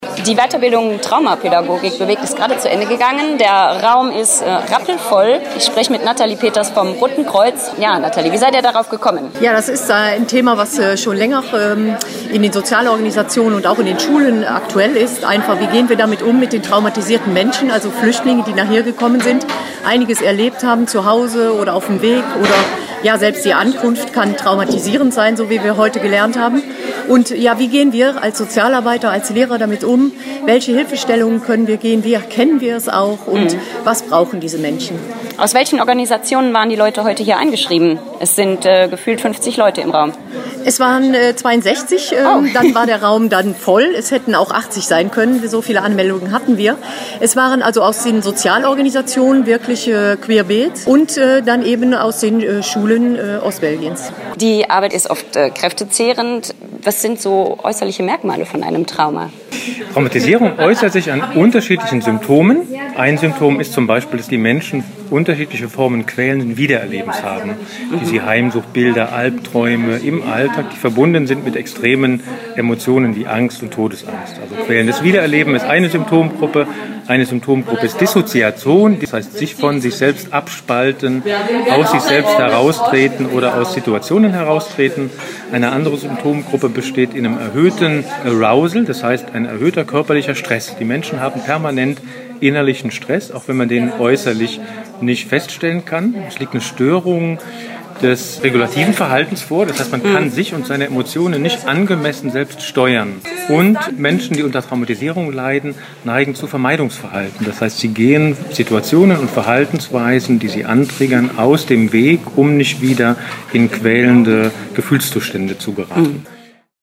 vor Ort